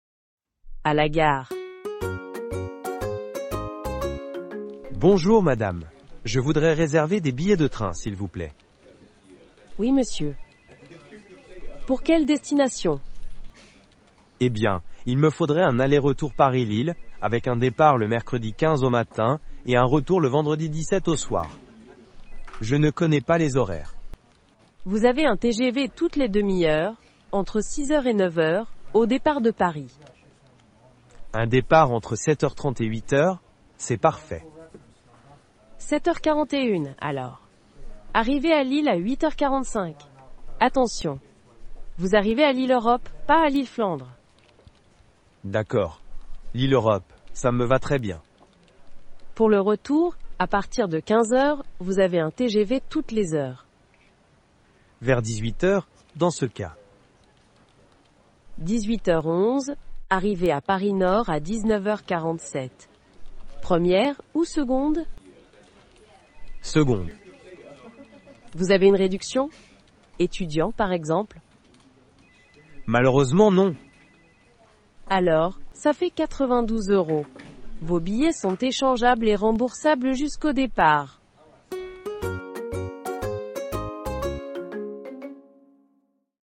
Dialogues en Français a2